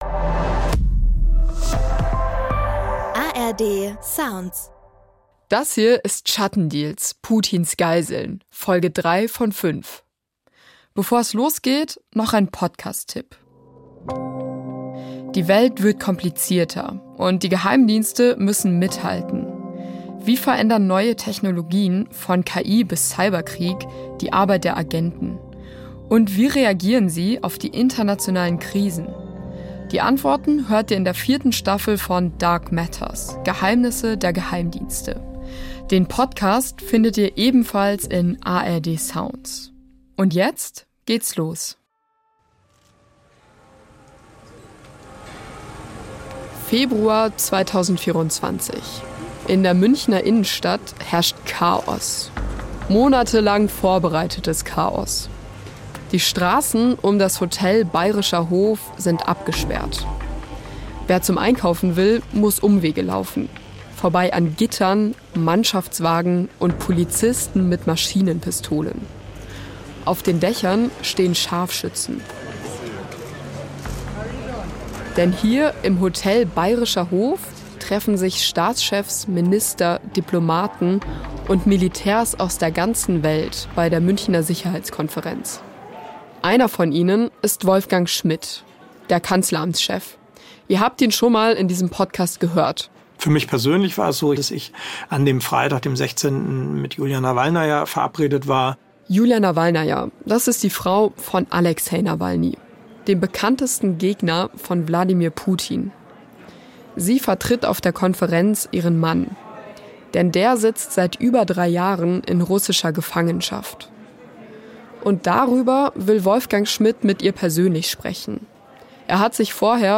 Hinweis zum Sound: In einzelnen Szenen haben wir Hintergrund-Geräusche nachgestellt. Alle Interview-Töne sind real.